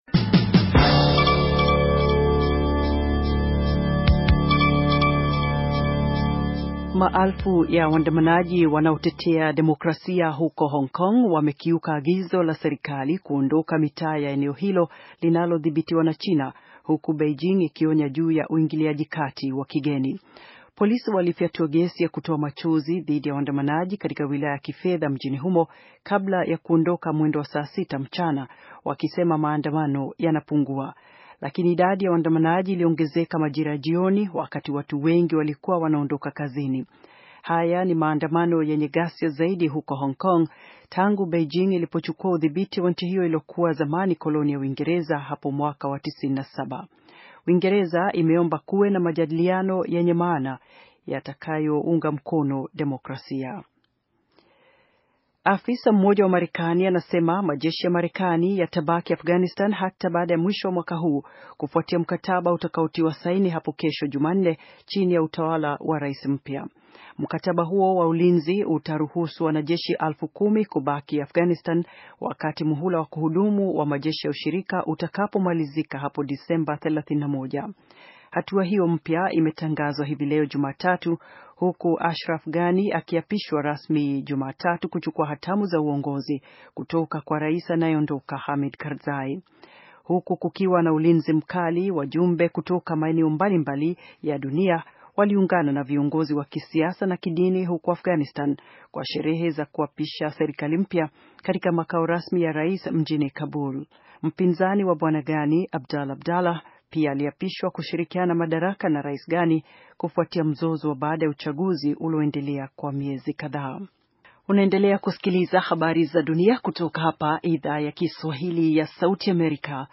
Taarifa ya habari - 5:53